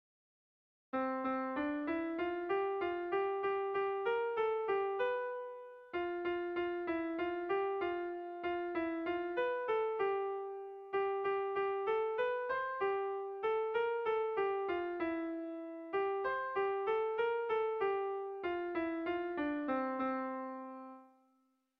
Kontakizunezkoa
Zortziko txikia (hg) / Lau puntuko txikia (ip)
ABDE